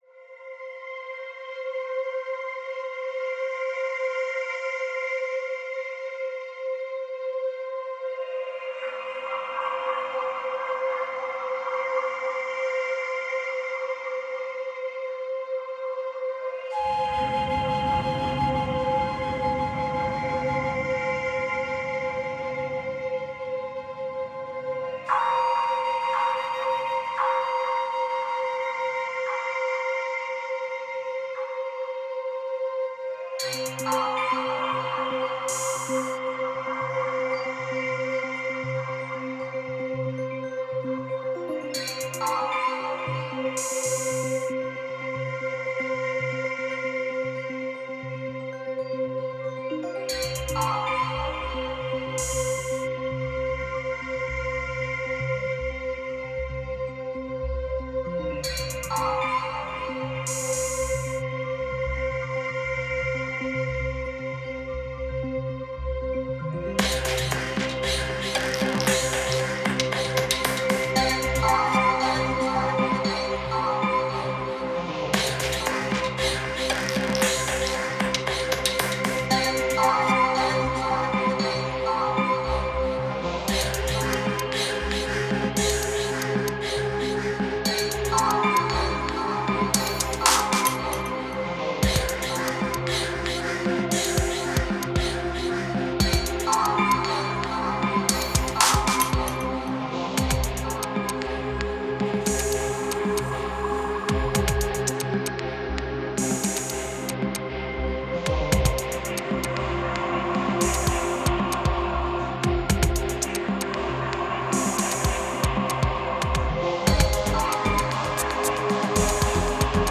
Genre: Electronic, IDM.